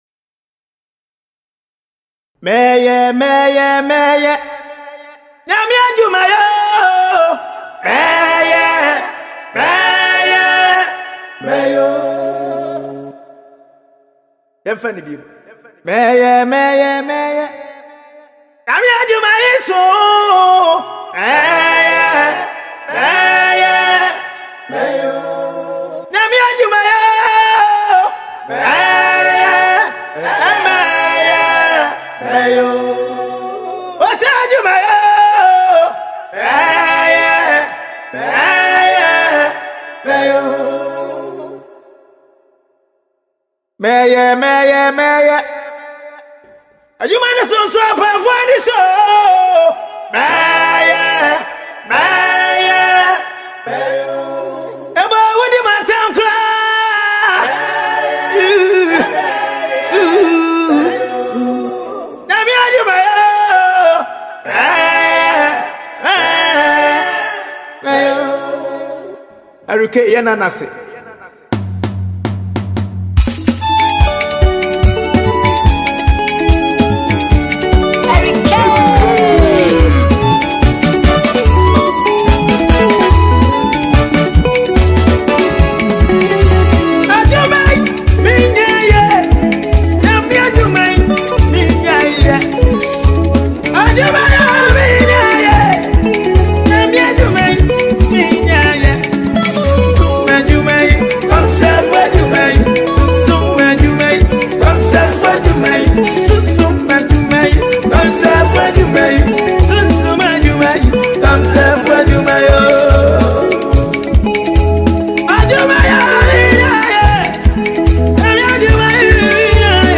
Award winning Ghanaian gospel singer